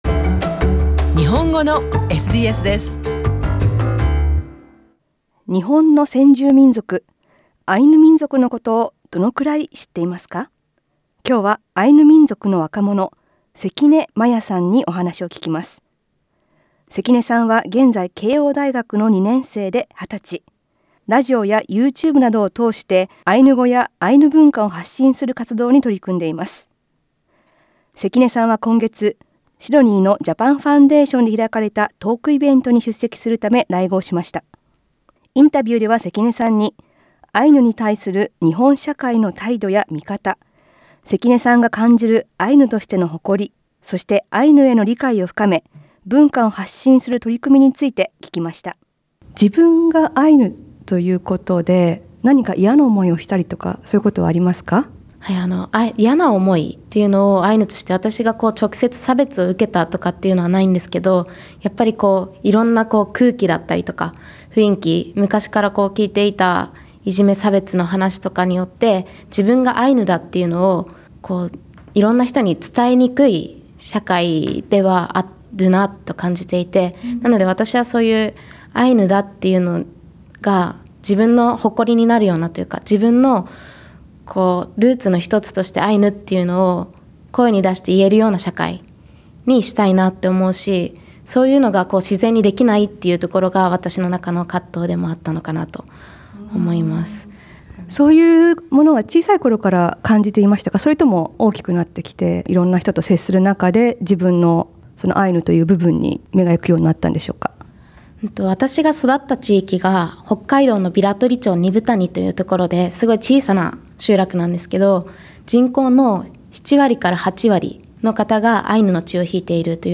インタビューでは、アイヌであることの誇りや、理解を深めてもらうための取り組み、アイヌ独特の考え方などを語っています。